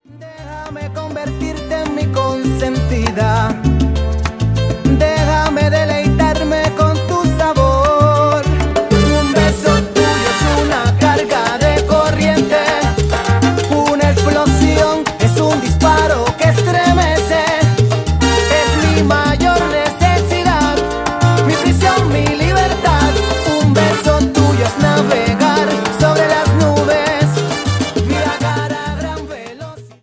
Salsa Charts - November 2008